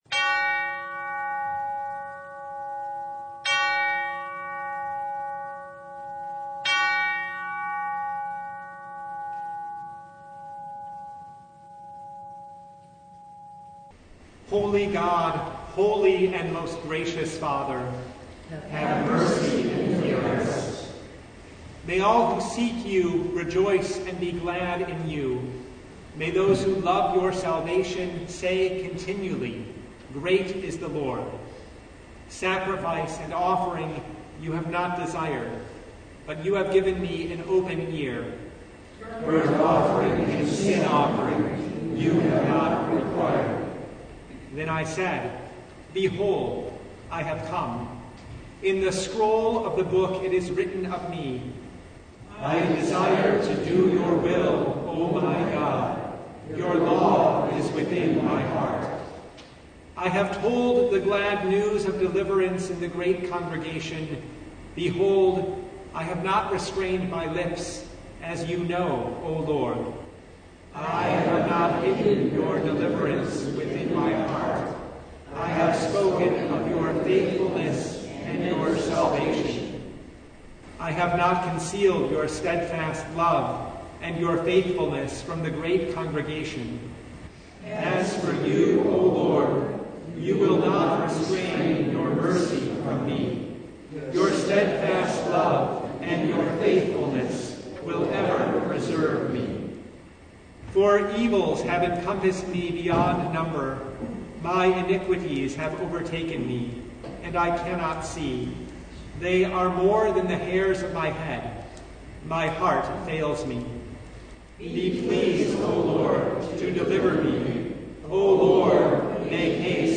The First Advent Noon Service (2022)